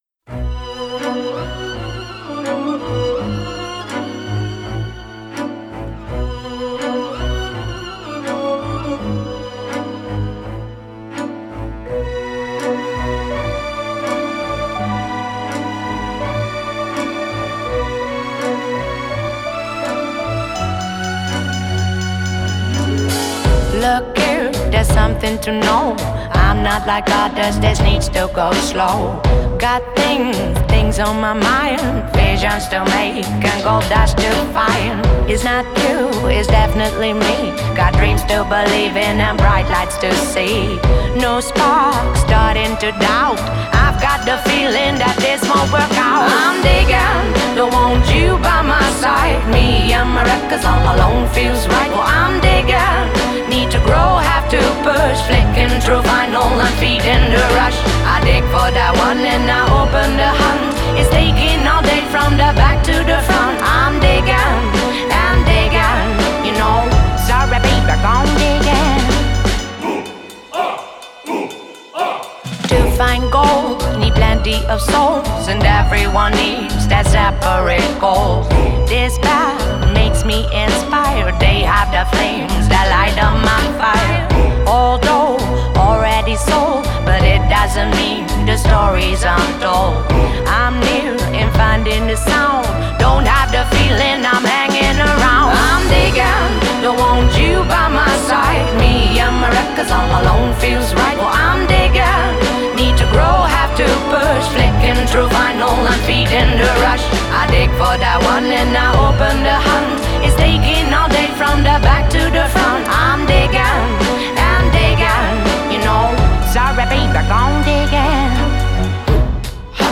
Genre: Indie Pop, Soul, Jazz